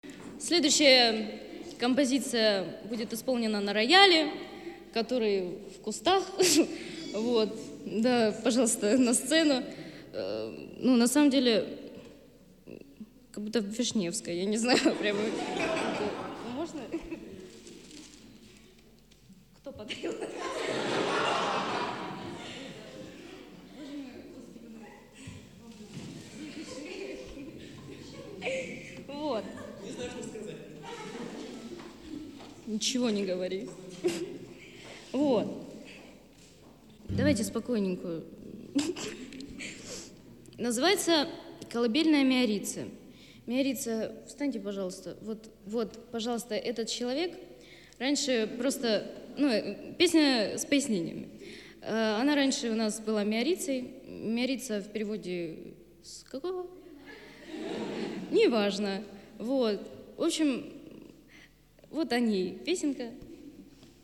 записанный на концерте пятого мая в галерее "Юг", г. Краснодар